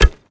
new sfx